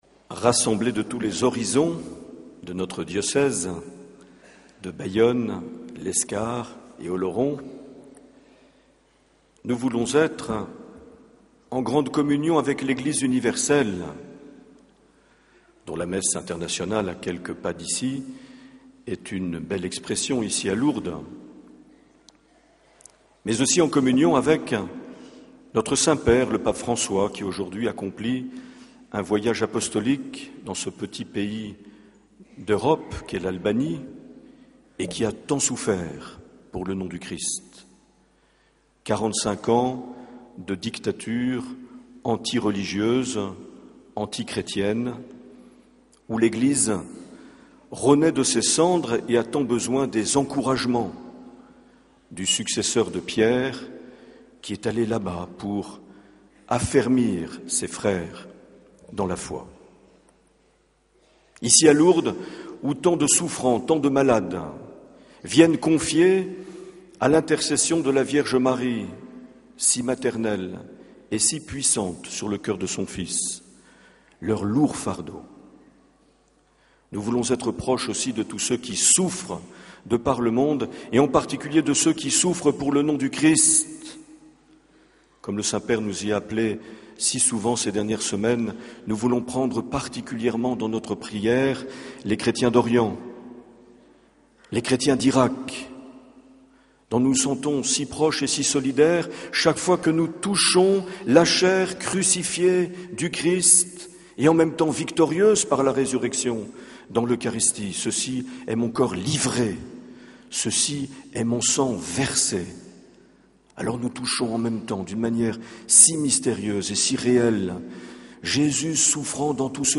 21 septembre 2014 - Lourdes Sainte Bernadette - Messe du pèlerinage diocésain
Accueil \ Emissions \ Vie de l’Eglise \ Evêque \ Les Homélies \ 21 septembre 2014 - Lourdes Sainte Bernadette - Messe du pèlerinage (...)
Une émission présentée par Monseigneur Marc Aillet